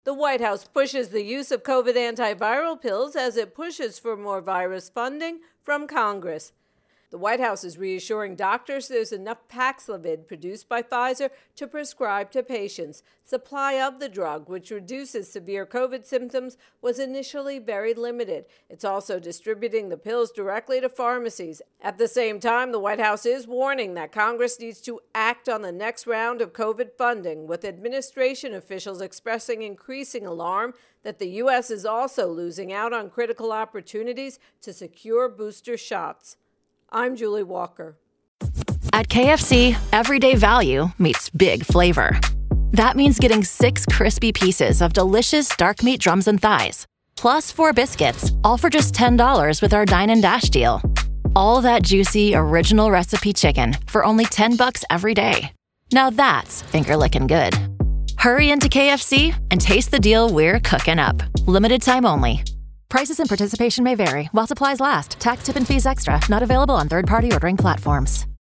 Virus Outbreak Biden intro and voicer